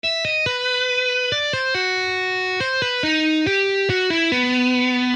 Pahoittelen kammottavaa tietokonesoundia.
Riffi 2